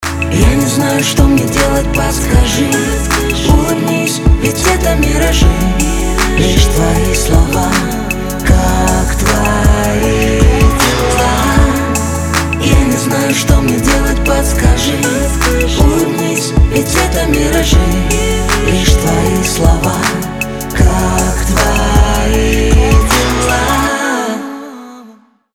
• Качество: 320, Stereo
поп
спокойные
дуэт